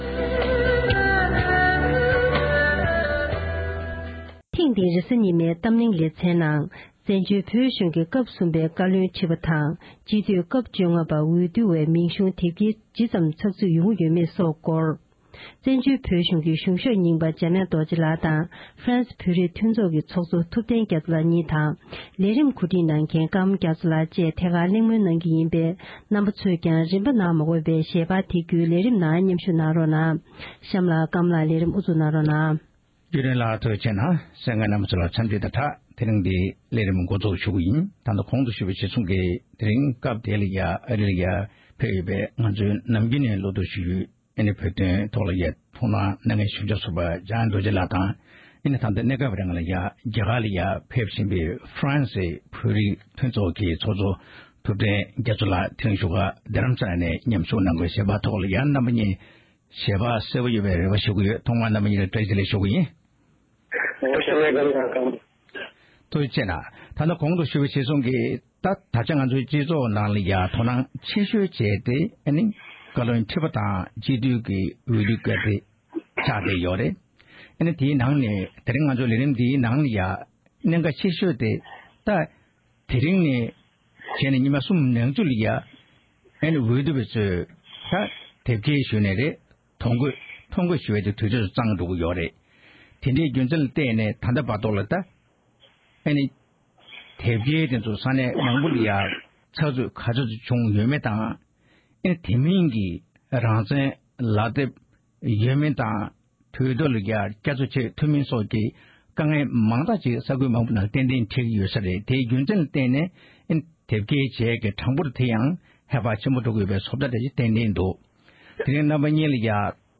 བཀའ་སྤྱིའི་འོས་བསྡུ་སྐོར་གླེང་མོལ།